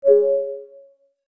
Notification.wav